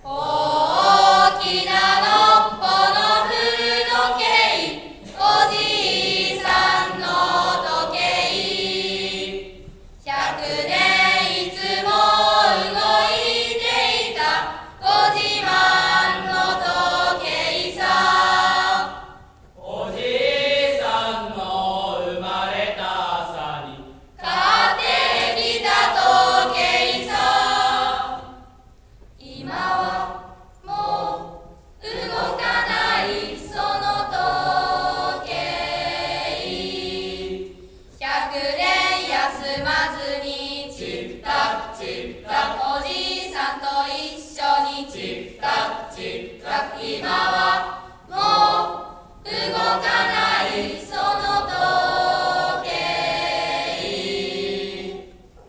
文化祭でのクラス合唱【音量に注意してください！】 3年5組「大きな古時計」